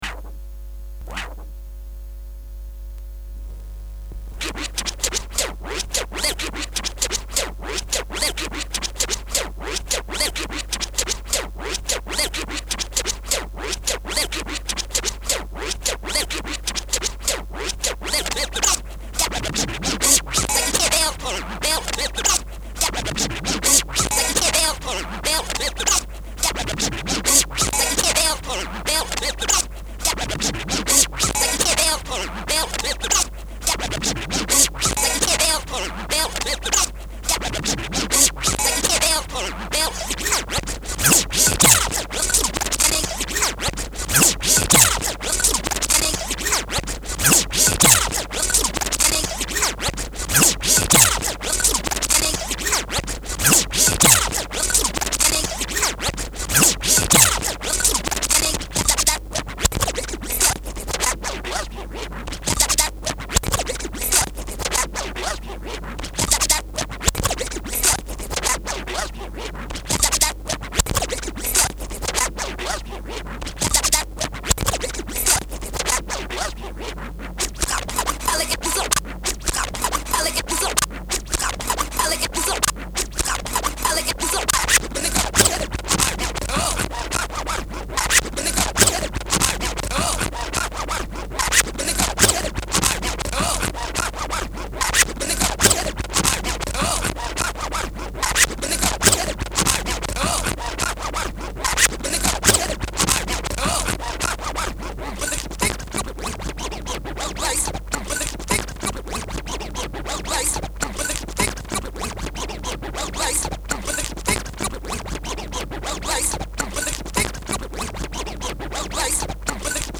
scratch